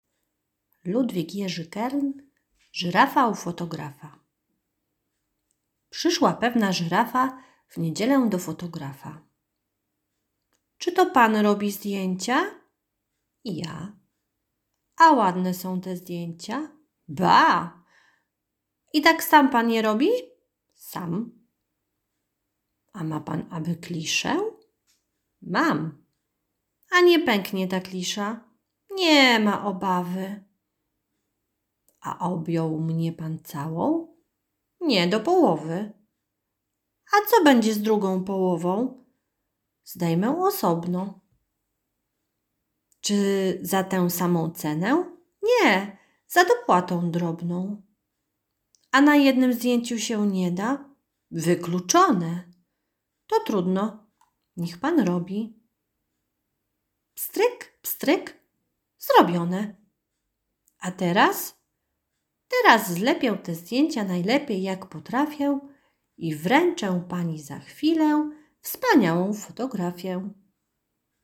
Wiersze